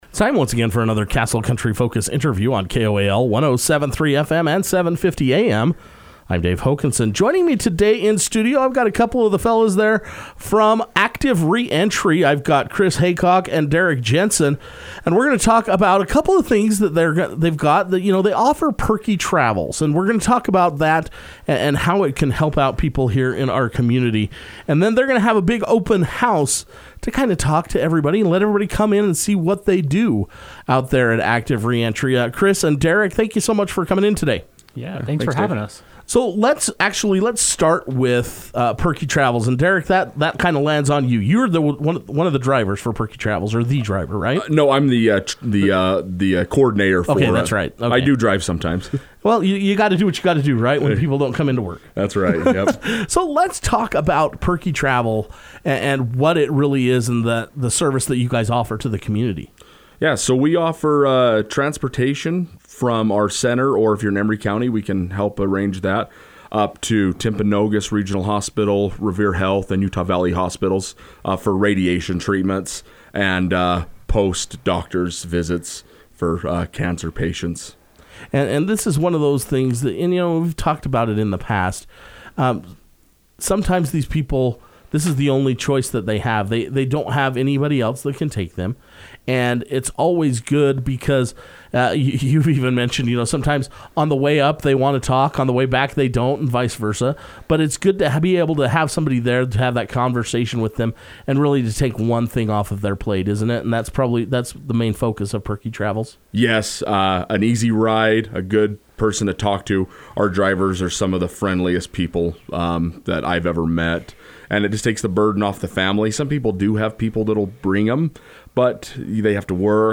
Active Re-Entry talks all things Perkie Travel